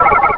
Cri de Chuchmur dans Pokémon Rubis et Saphir.